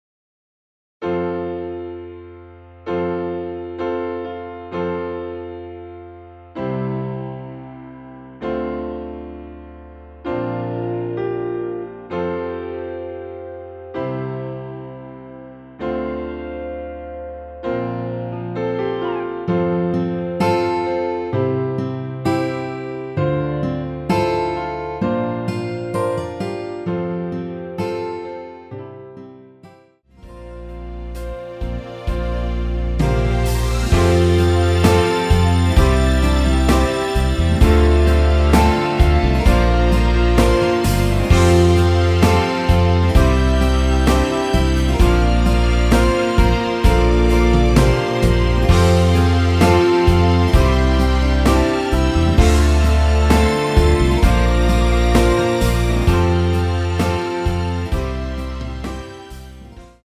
내린 MR 입니다.
노래가 바로 시작 하는곡이라 전주 1마디 만들어 놓았습니다.(미리듣기 참조)
앞부분30초, 뒷부분30초씩 편집해서 올려 드리고 있습니다.
중간에 음이 끈어지고 다시 나오는 이유는